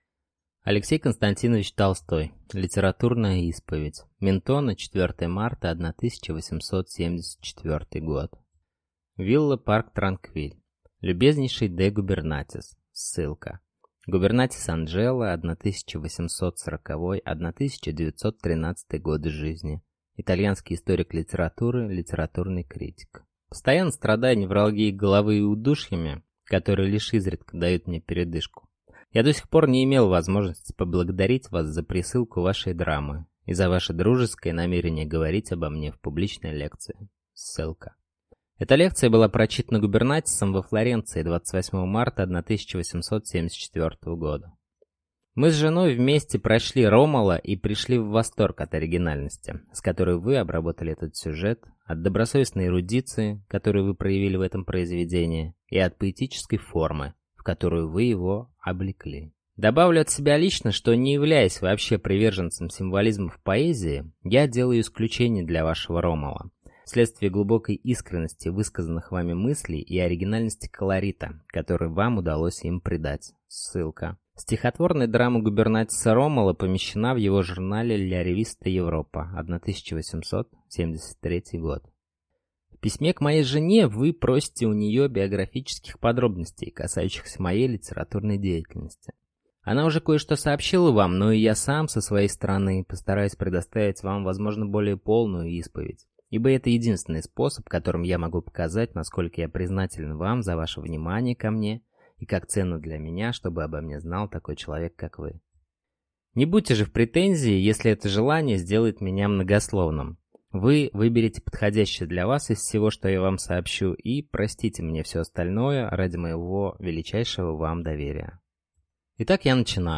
Аудиокнига Литературная исповедь | Библиотека аудиокниг